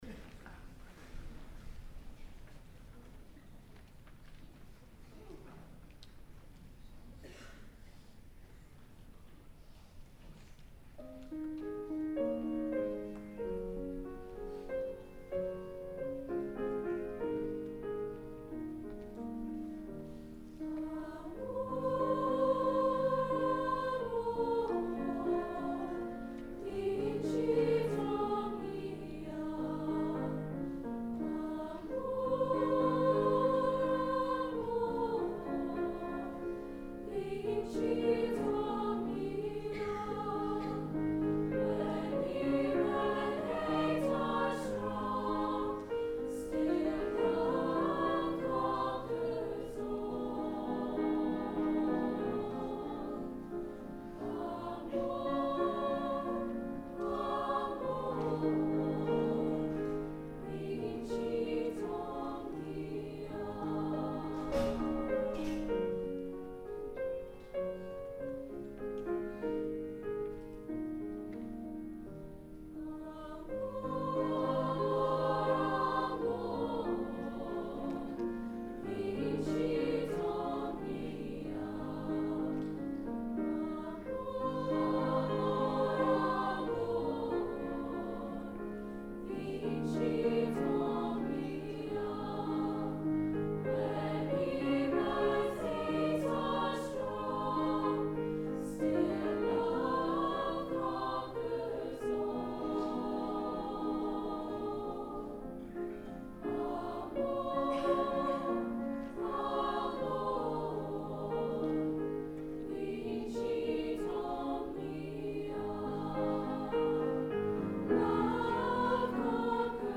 Spring Concert Tracks 2017